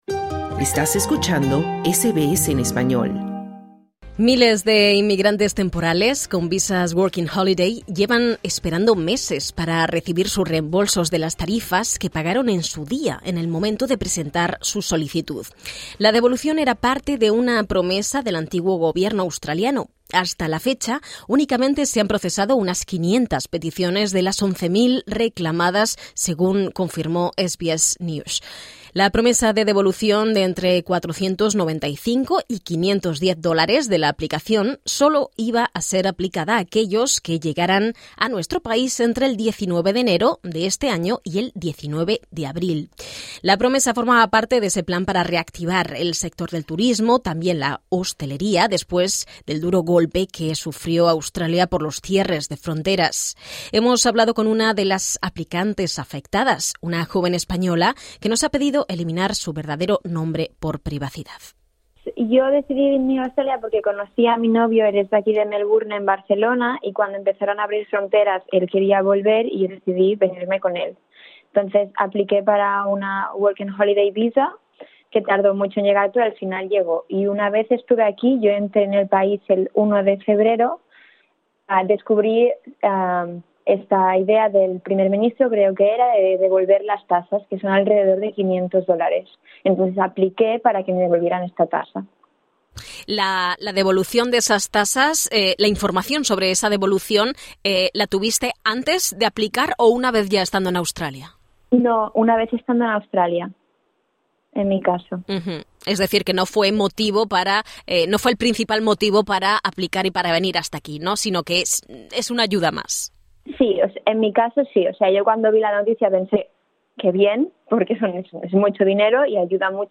Los titulares de visas Working Holiday han hecho un llamamiento al gobierno de Australia para que cumpla su promesa de reembolsar las tarifas de solicitud, después de una larga demora durante la cual solo el 5 por ciento recibió sus devoluciones. SBS Spanish conversó con una estudiante española afectada por los retrasos del sistema.